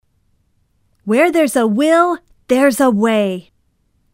education speech reading language translation